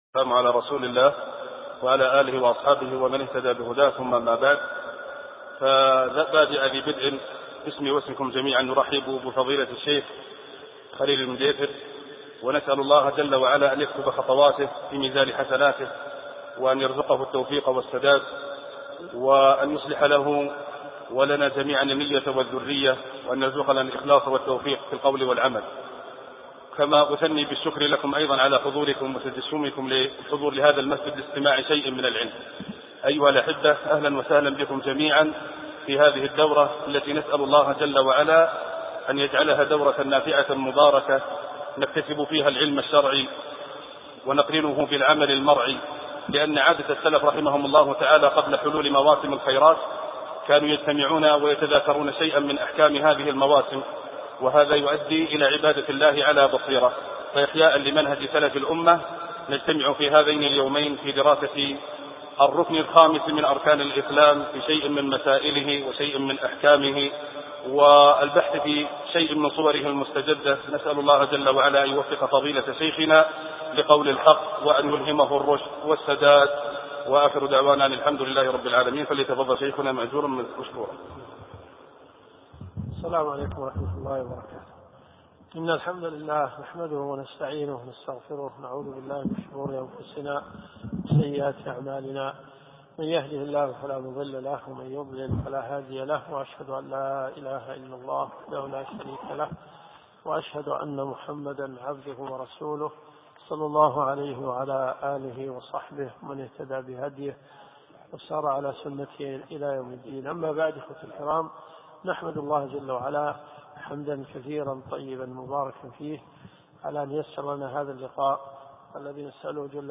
الدروس الشرعية